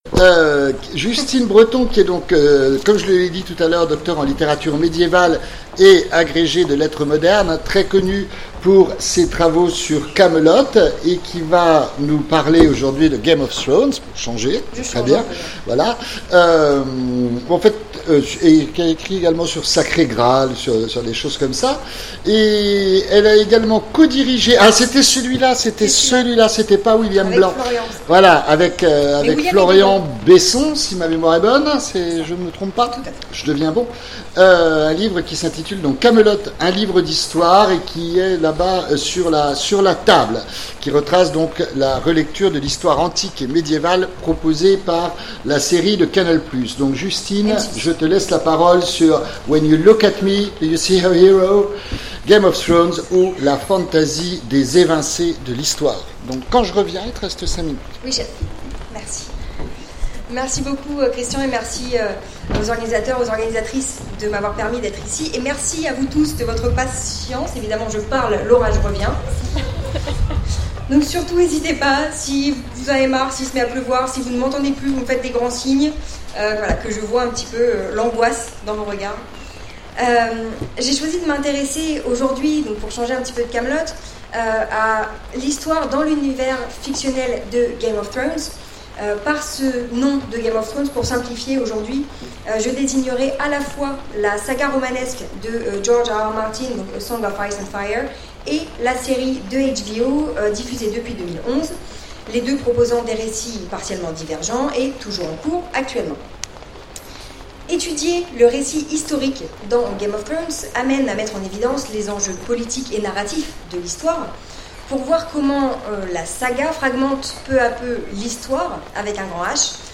Colloque universitaire 2018 : When you look at me, do you see a hero ?